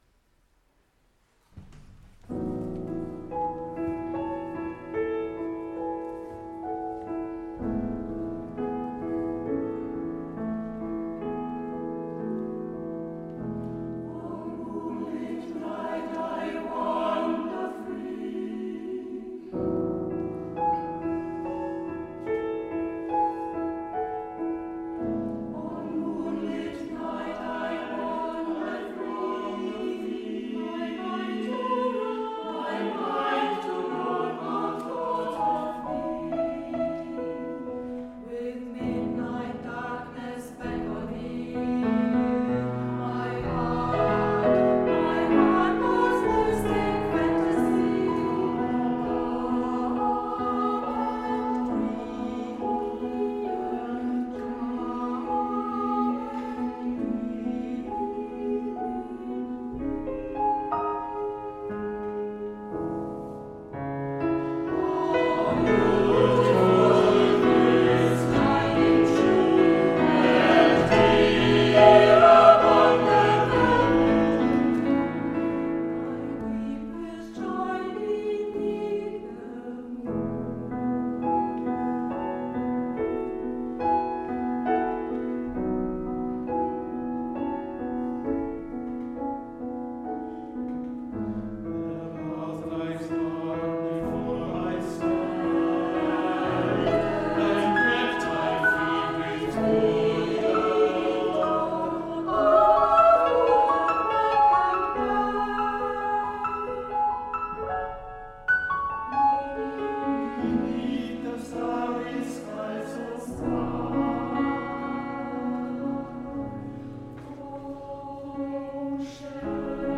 2023 bereicherte Incantiamo das Konzert des Wasserburger Bach-Chores mit Orffs CARMINA BURANA und sang im 1. Teil Klavierlieder von Chilcott, Elder, Shore und Jenkins.
04_Elder_Ballade_to_the_moon_mit_Hall-mittlerer_Raum.mp3